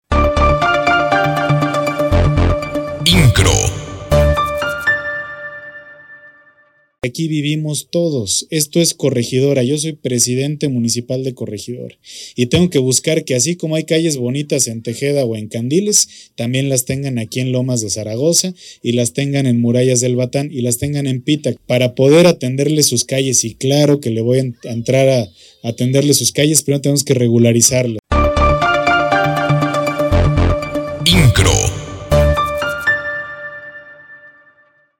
Frase Destacada del Presidente Municipal: